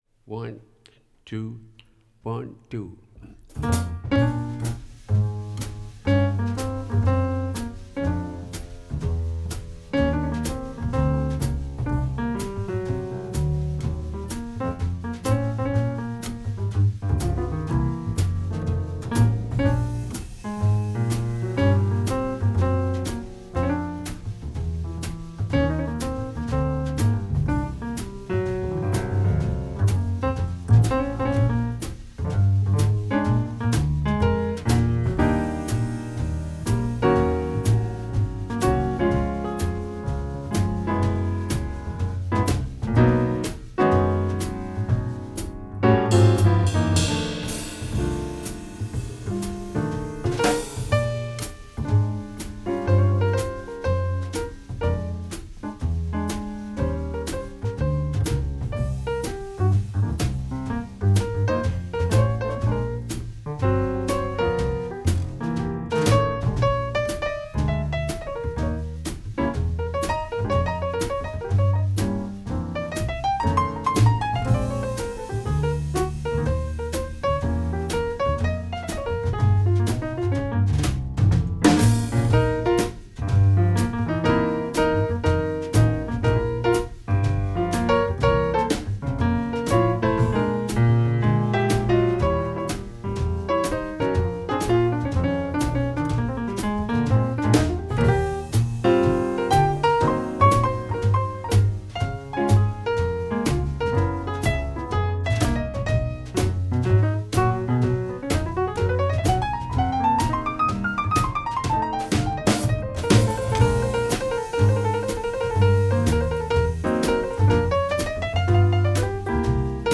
48 kHz mit Ayre-Wandler